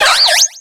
Cri d'Escargaume dans Pokémon X et Y.